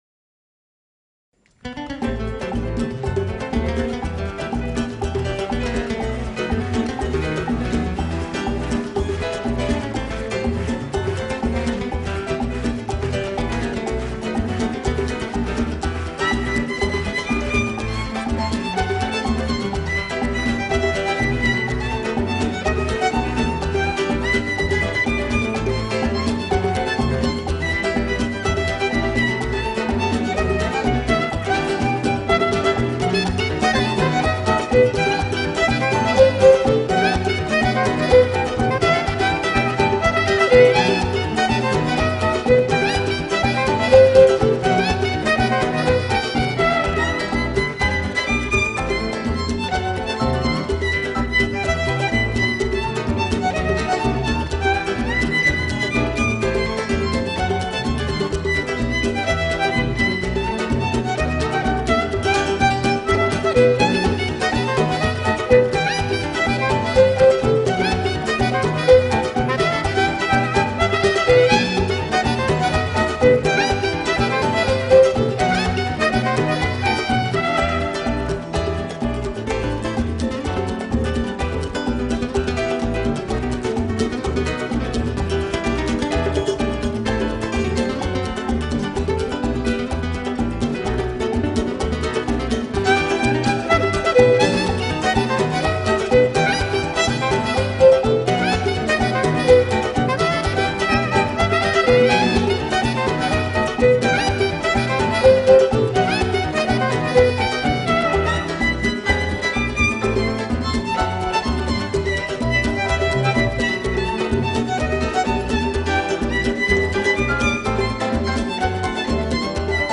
Скрипки..